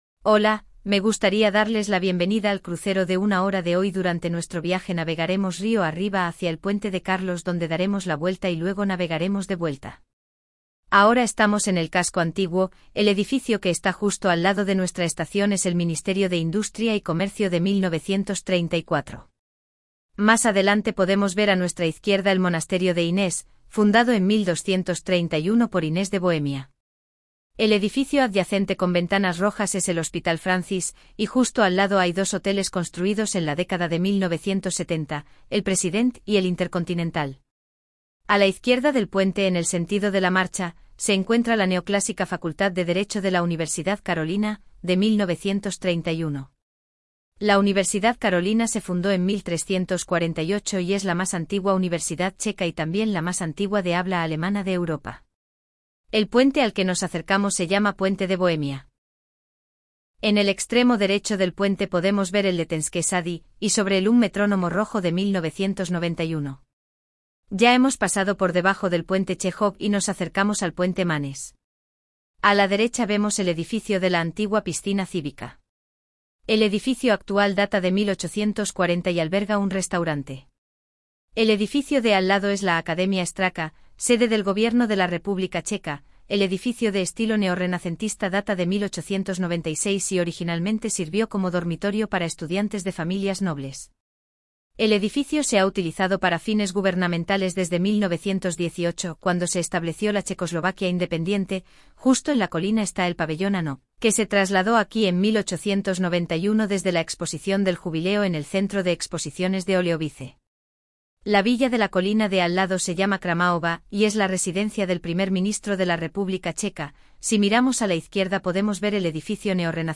Moderní tříjazyčný komentář obsahující informace o lodi a řece Vltavě.
Modern trilingual commentary containing information about the ship and the Vltava River